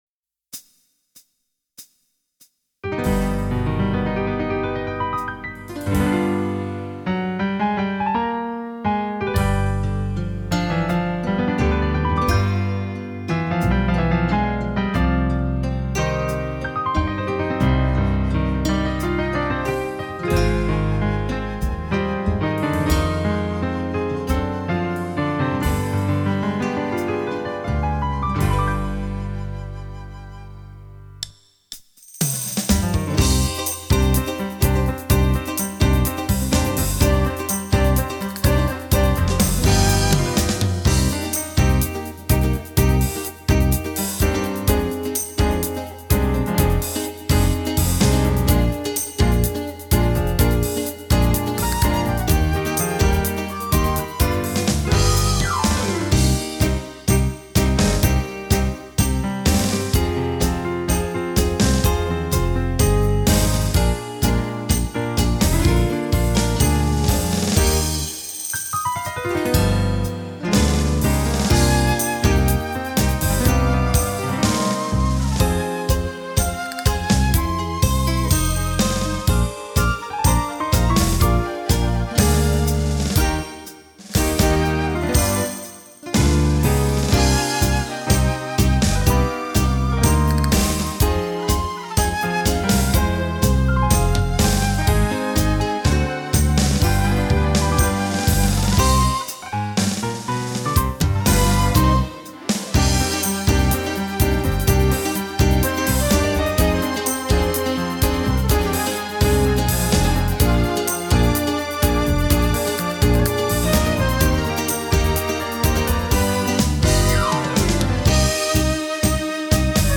New tango
Fisarmonica